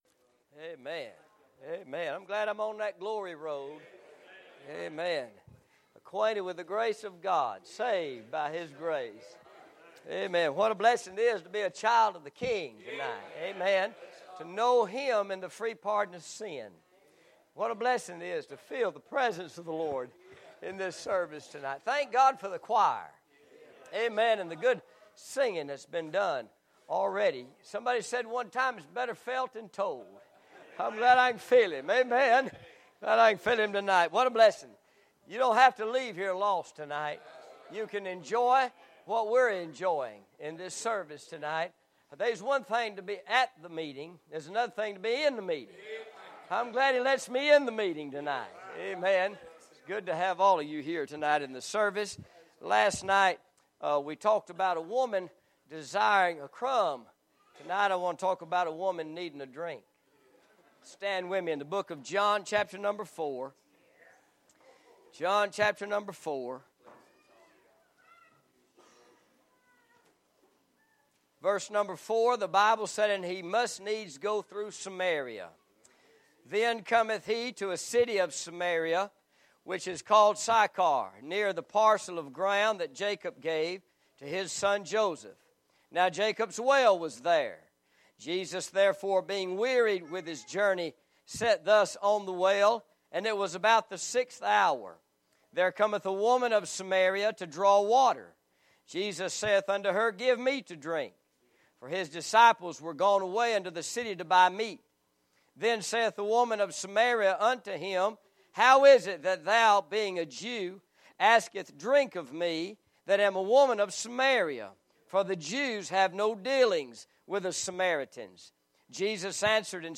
Message-In-Jacobs-Well-There-Is-Nothing-But-Water.mp3